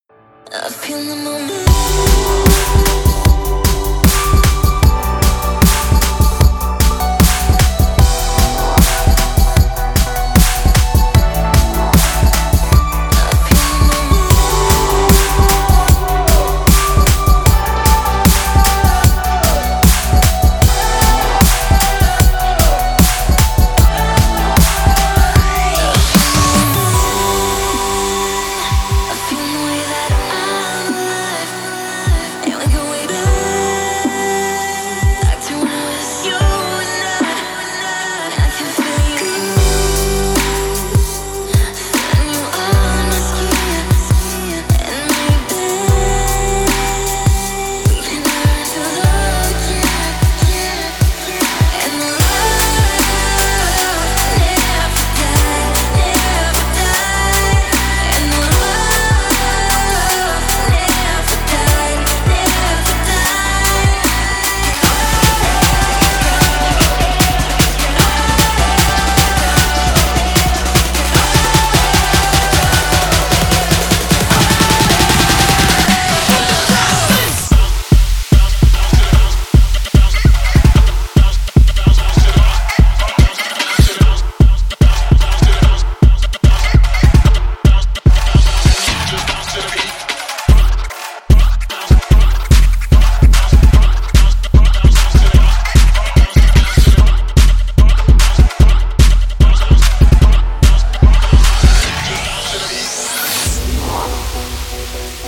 Genre: HIPHOP Version: Clean BPM: 105 Time